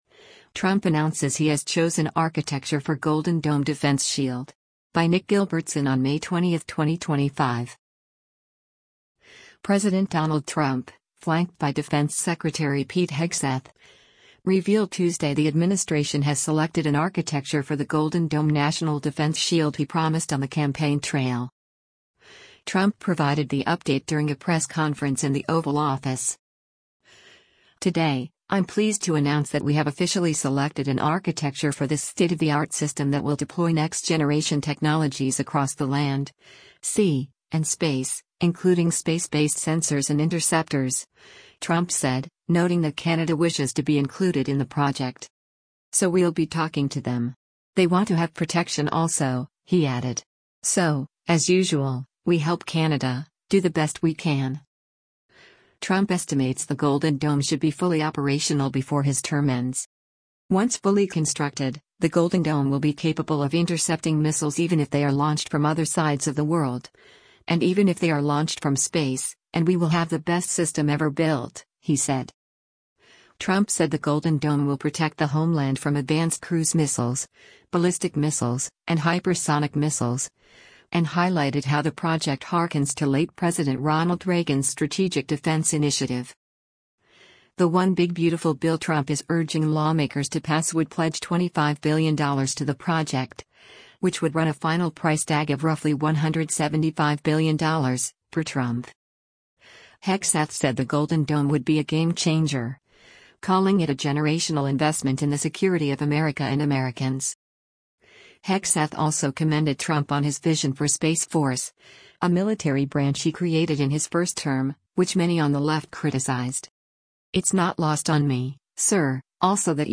U.S. President Donald Trump speaks alongside Secretary of Defense Pete Hegseth in the Oval
Trump provided the update during a press conference in the Oval Office.